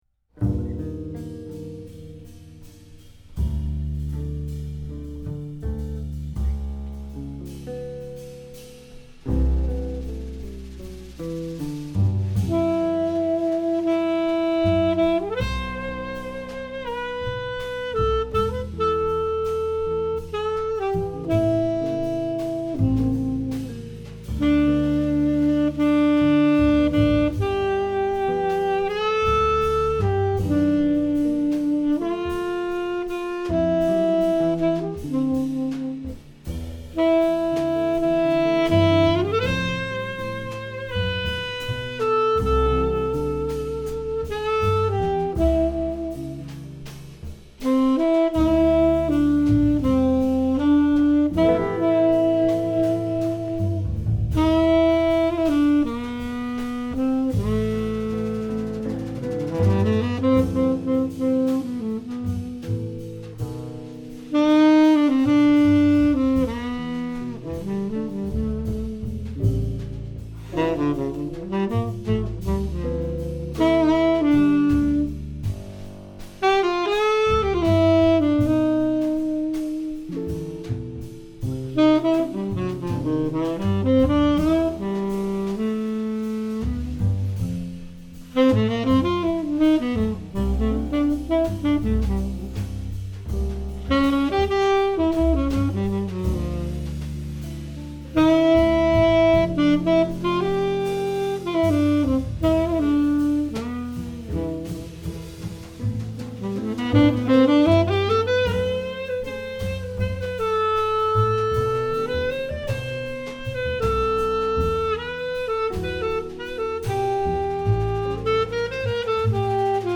tenor saxophone
guitar
bass
drums